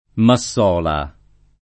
[ ma SS0 la ]